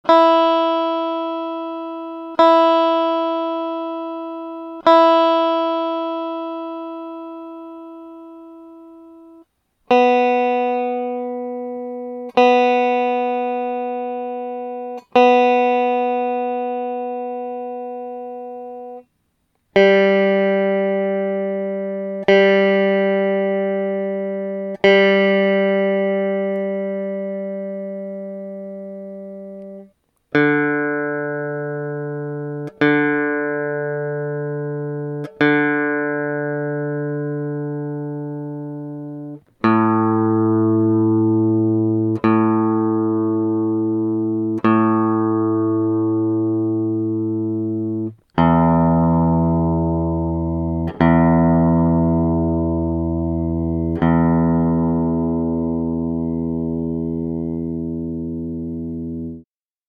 Камертон звук ля 440 герц